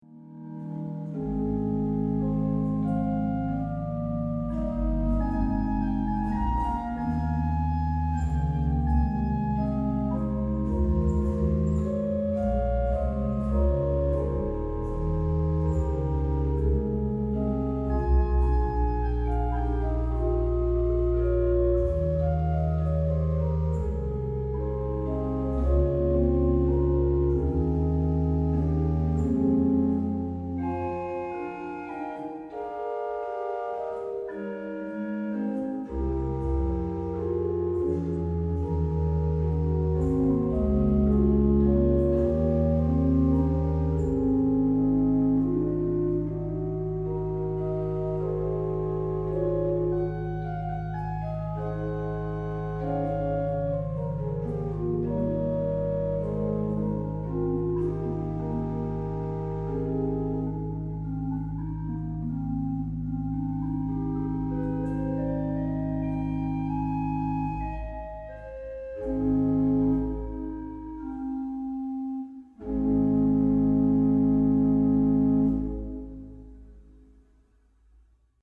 Levyltä saa hurmaavan kuvan soittimesta, sen suloisista
huiluista